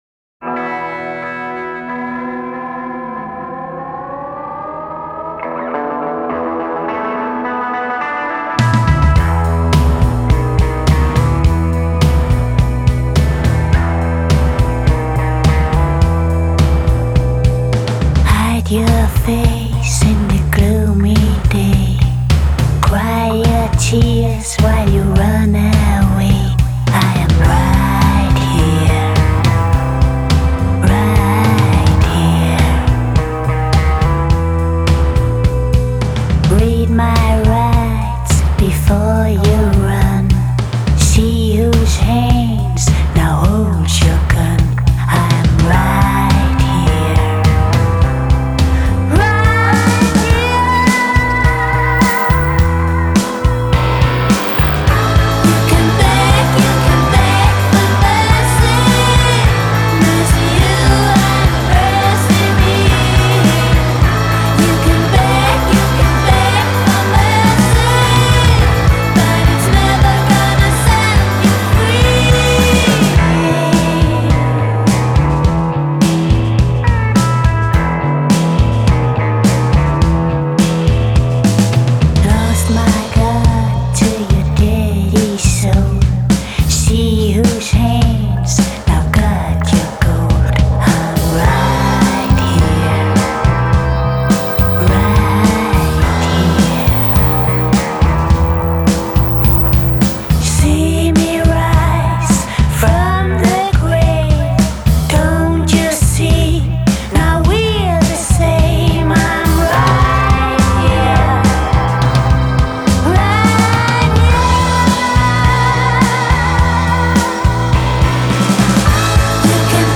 catbird-beg-for-mercy.mp3